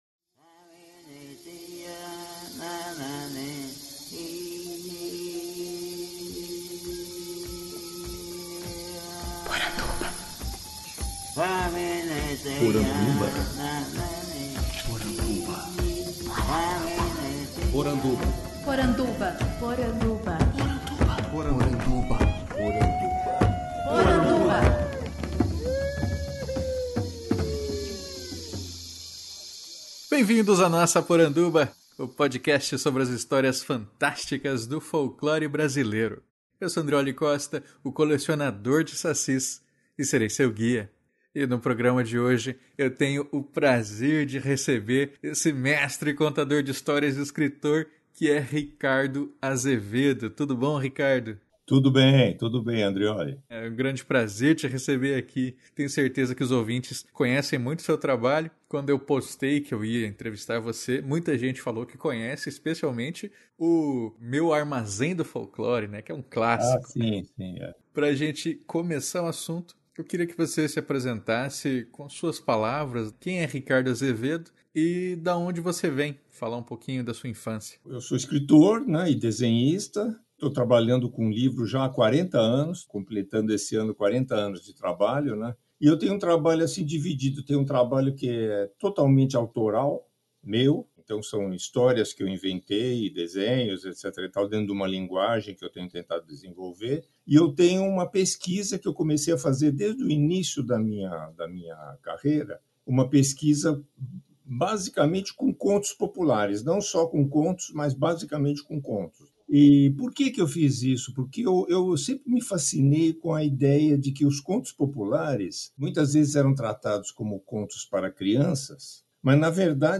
Podcast que entrevista o escritor